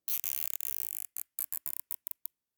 household
Zip Ties Secure 10